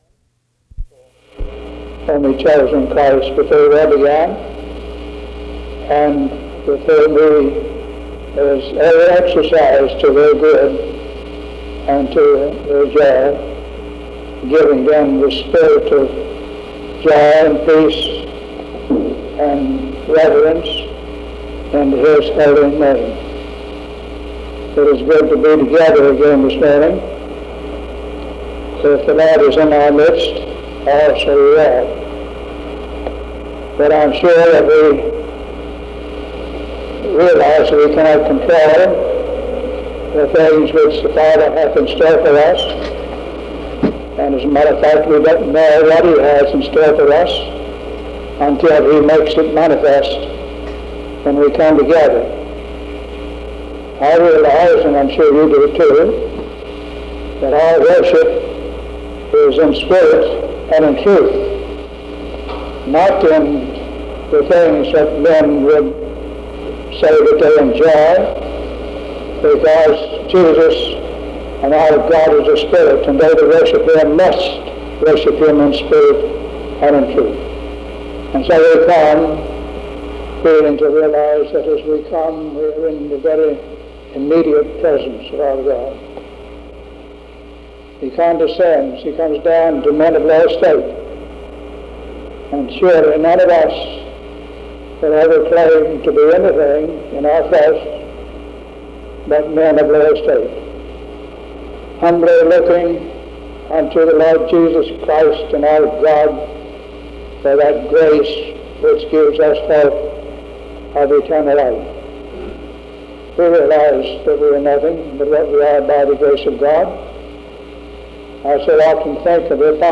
A short clip from an unknown service